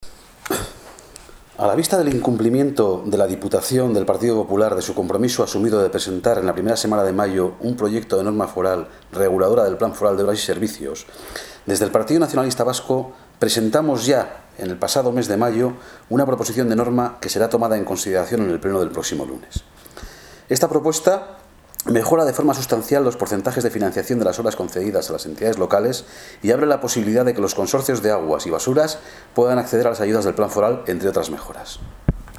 Juntas Generales Araba: Ramiro Gonzalez sobre las proposición de Norma de Plan Foral